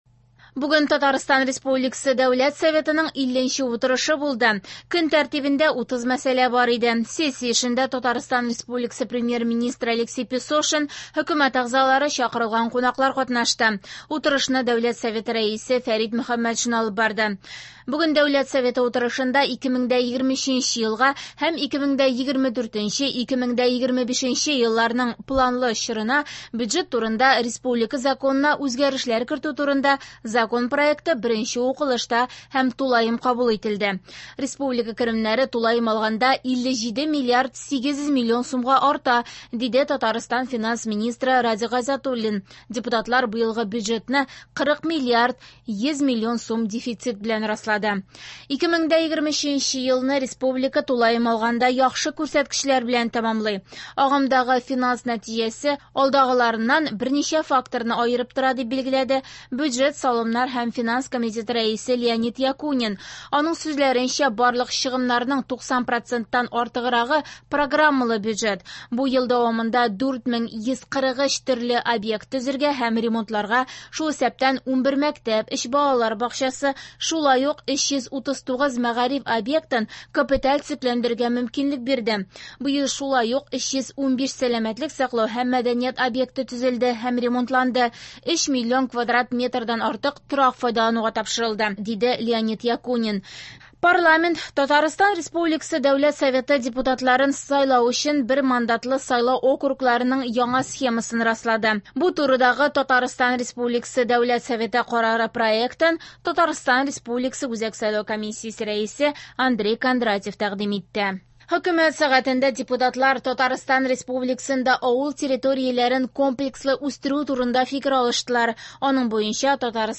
Радиоотчет (18.12.23) | Вести Татарстан
В эфире специальный информационный выпуск, посвященный 50 заседанию Государственного Совета Республики Татарстан 6-го созыва.